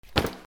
カバンを落とす
/ J｜フォーリー(布ずれ・動作) / J-10 ｜転ぶ　落ちる